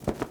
SFX_Duck_Wings_05.wav